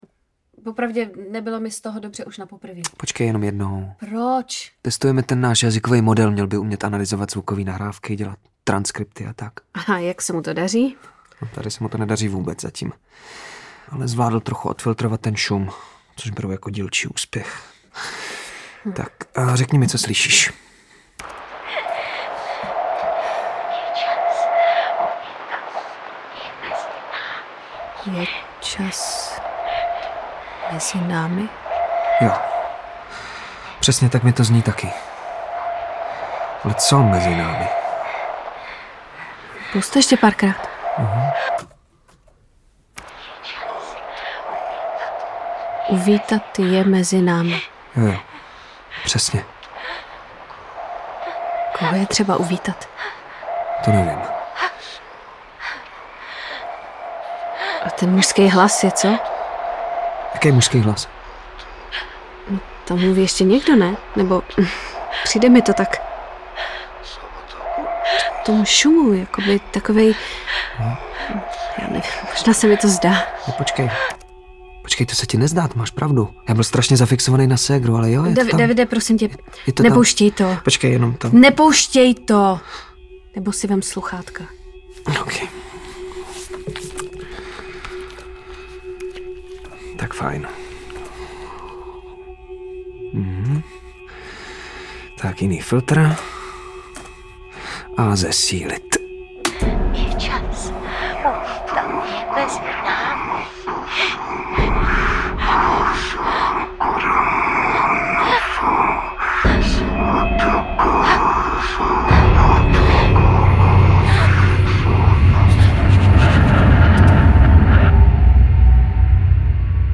Audiobook
Read: Různí interpreti